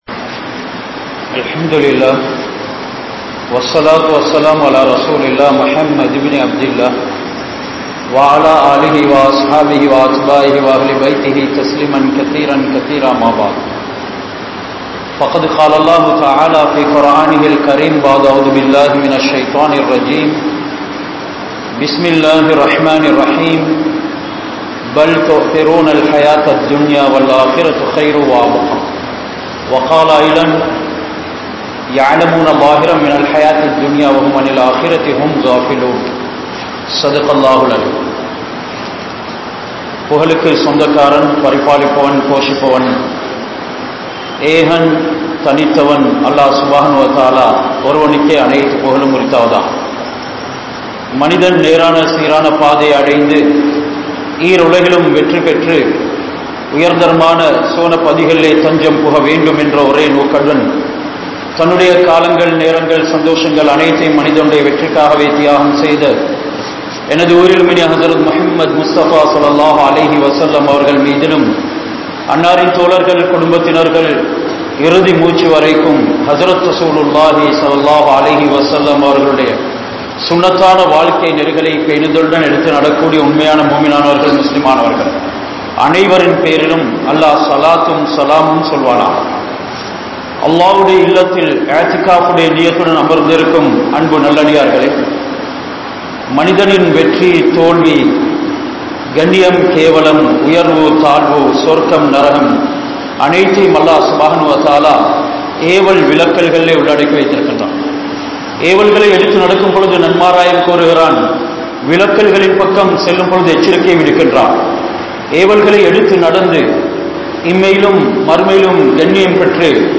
Munaafiq Entraal Yaar? (முனாபிக் என்றால் யார்?) | Audio Bayans | All Ceylon Muslim Youth Community | Addalaichenai